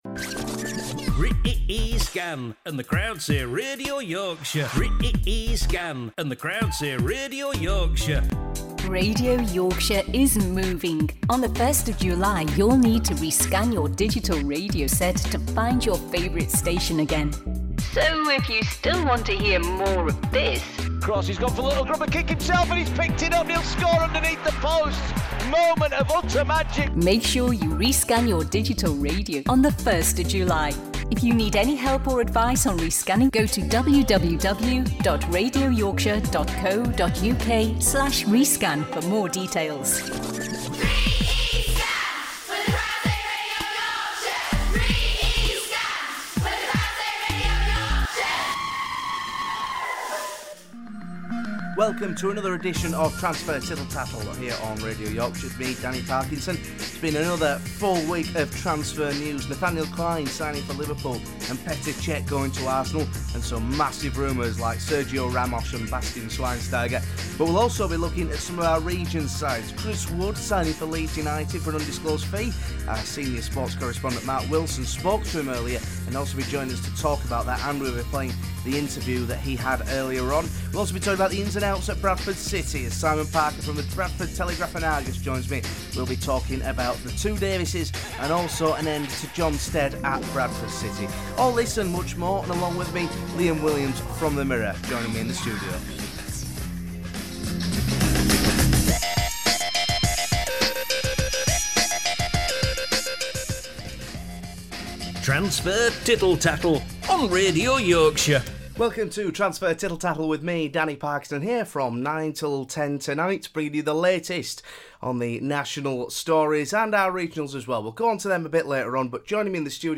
joins him in the studio to talk about the latest rumors and news